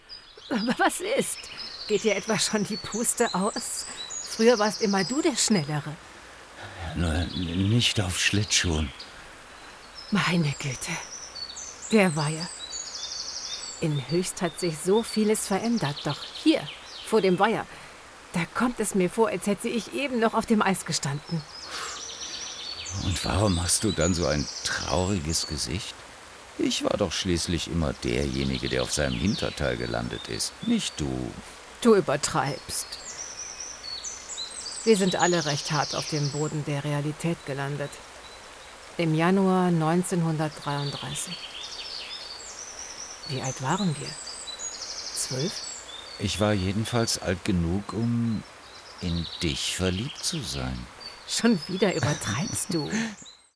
Profonde, Naturelle, Polyvalente, Chaude, Corporative
Commercial
Elle dispose d’un studio professionnel à domicile et d’une solide expérience avec de grandes marques.